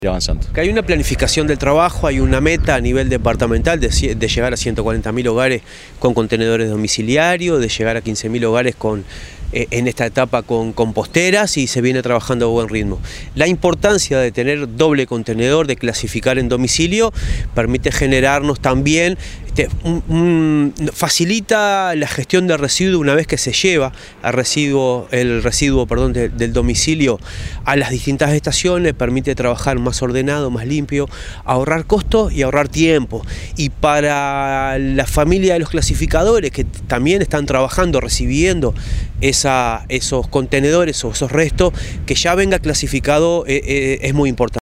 El Intendente interino de Canelones, Marcelo Metediera, participó de la entrega de contenedores para clasificar y composteras en un complejo de cooperativas de viviendas en Empalme Olmos. El jerarca sostuvo que el Plan de Gestión de Residuos Domiciliarios lleva una planificación de trabajo que se viene cumpliendo.
intendente_interino_de_canelones_marcelo_metediera_0.mp3